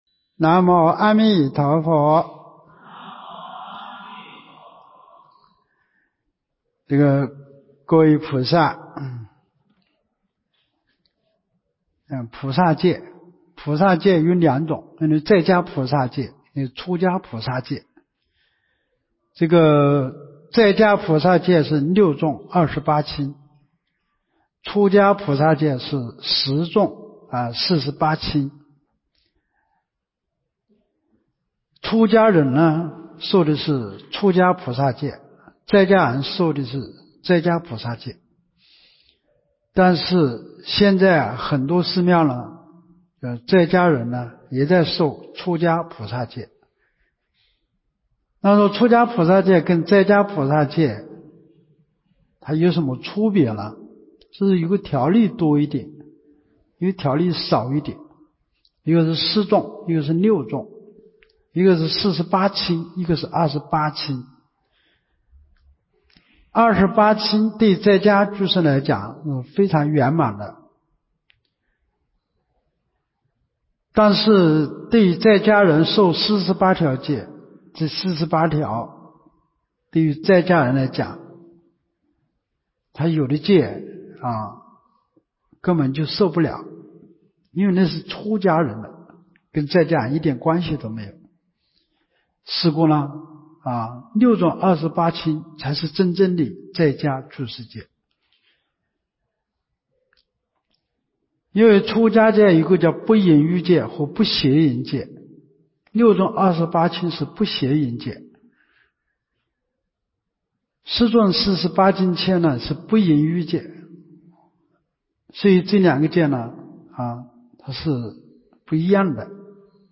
2024年陆丰学佛苑三皈五戒菩萨戒开示（1）